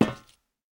immersive-sounds / sound / footsteps / rails / rails-12.ogg
rails-12.ogg